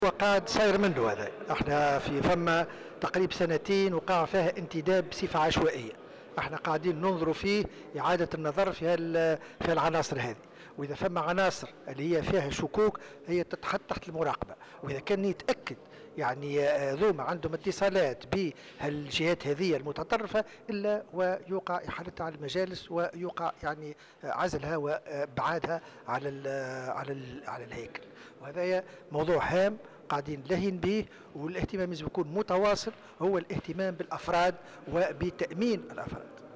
وقال الشلي في تصريح لمراسل "جوهرة أف أم" خلال زيارة عمل أداها اليوم لولاية المهدية أنه سيتم اتخاذ الإجراءات الضرورية في صورة ثبوت وجود علاقة بين بعض العناصر الأمنية وجهات متطرفة وإحالتها على المجالس وعزلها،وفق تعبيره.